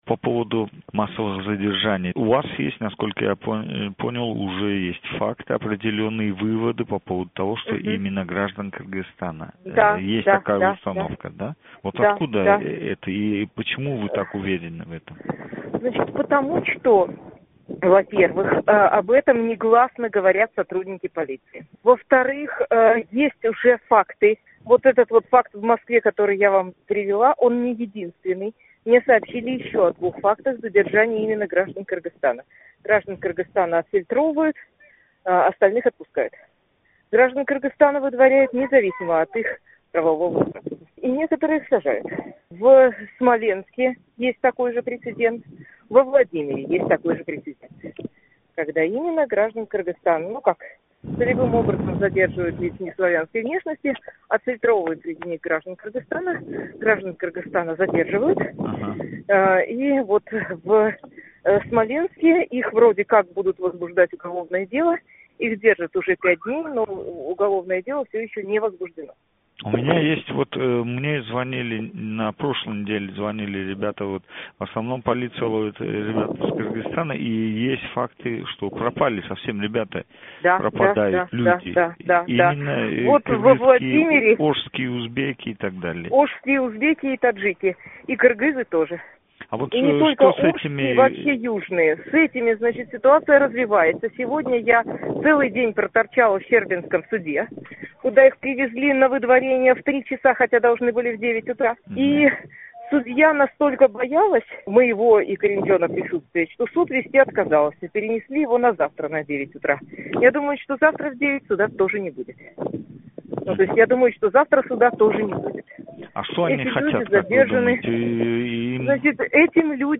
тўлиқ суҳбат